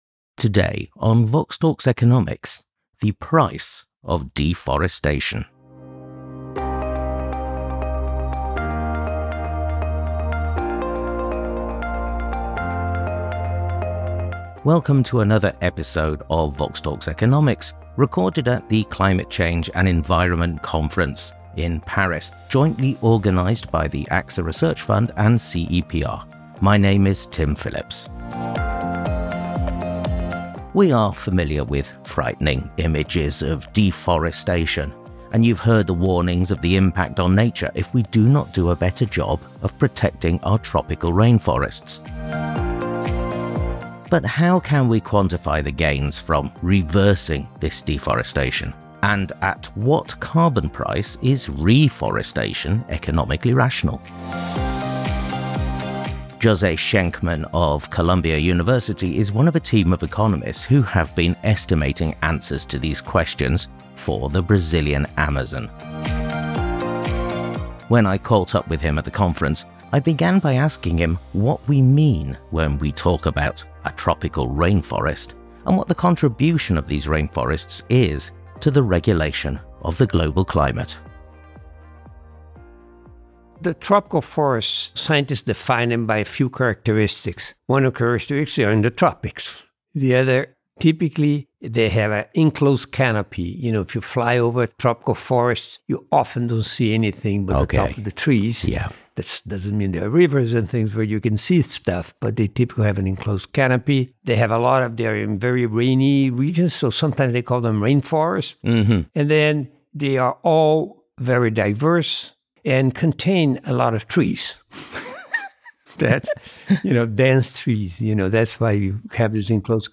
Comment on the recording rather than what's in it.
Recorded at the Climate Change and the Environment Conference, organised by the AXA Research Fund and CEPR.